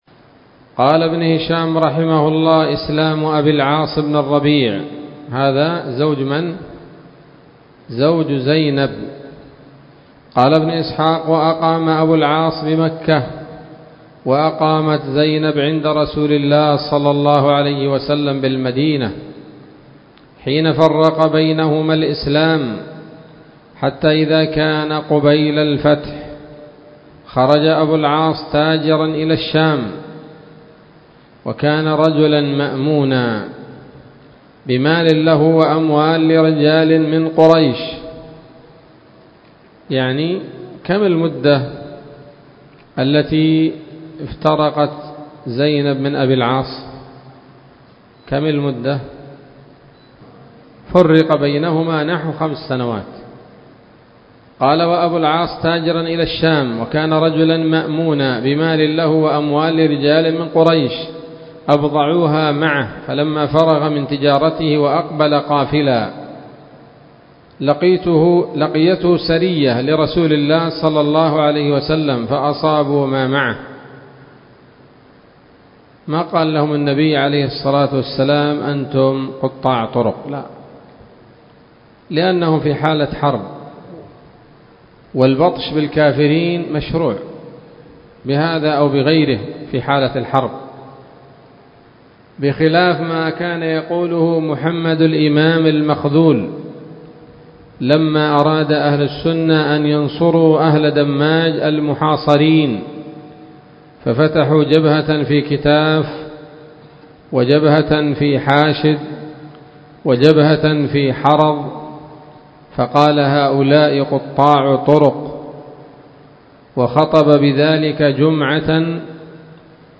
الدرس السابع والعشرون بعد المائة من التعليق على كتاب السيرة النبوية لابن هشام